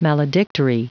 Prononciation du mot maledictory en anglais (fichier audio)
Prononciation du mot : maledictory